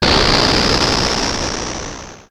Machine04.wav